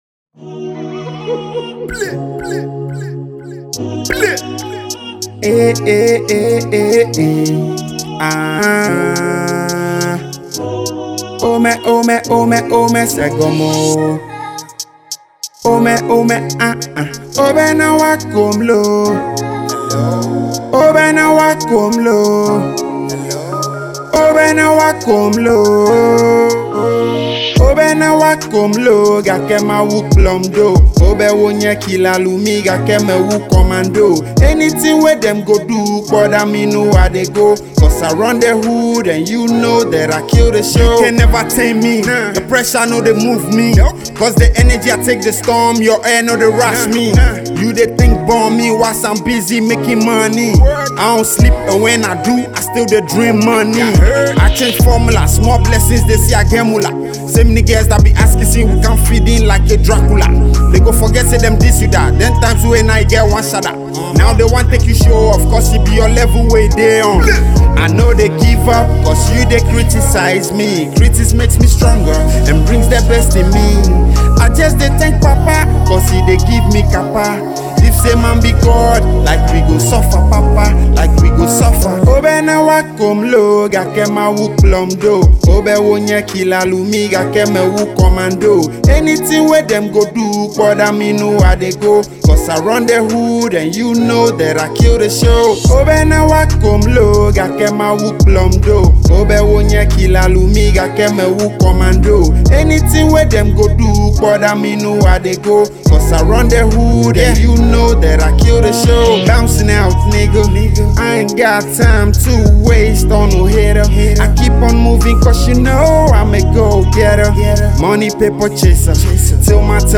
Hip Hop and Afrobeats